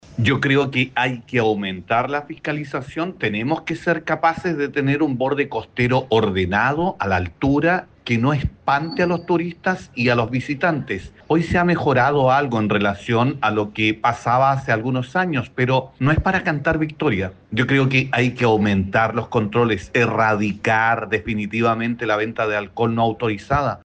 Al respecto, Sandro Puebla, concejal de la ciudad jardín e independiente de la bancada socialista, indicó que hay que aumentar la fiscalización y ser capaces de tener un borde costero ordenado para aumentar la afluencia de turistas en la zona.